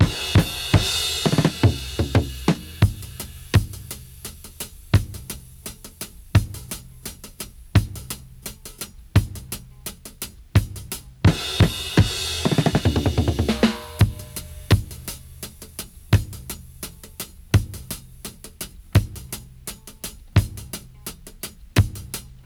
85-DRY-03.wav